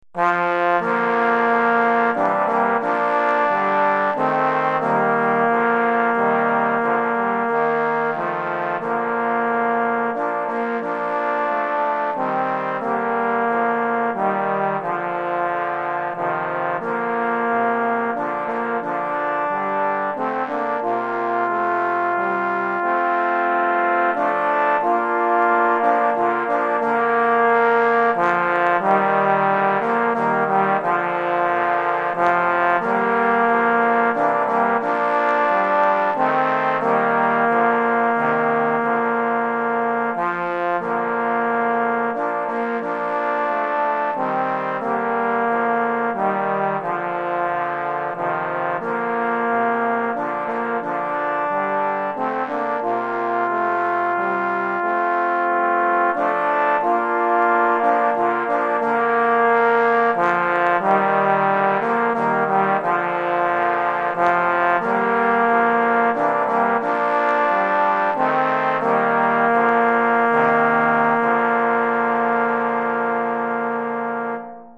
4 Trombones